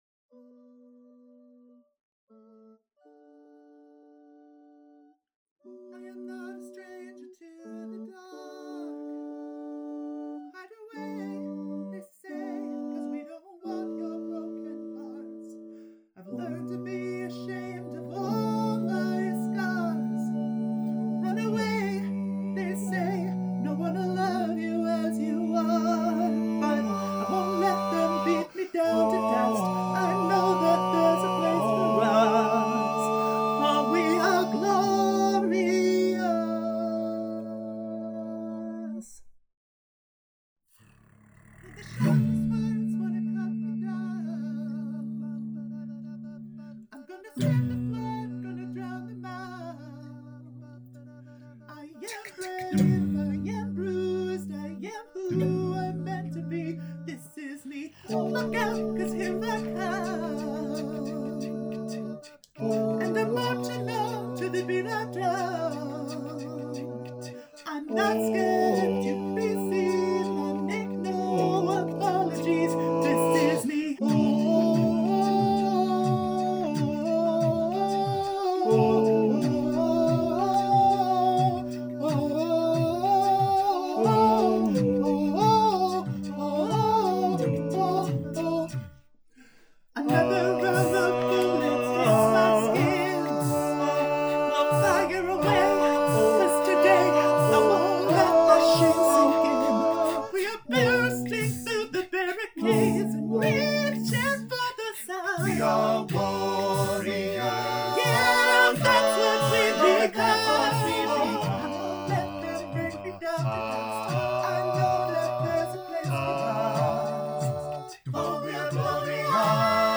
Harmony ReChoired (chorus)
Up-tempo
SATB
D Major